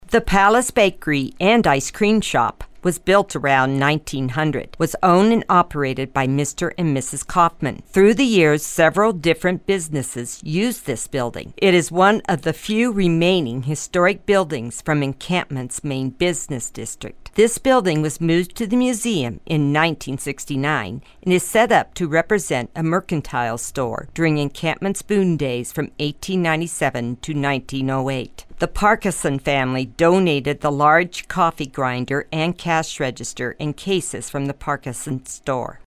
Audio Tour: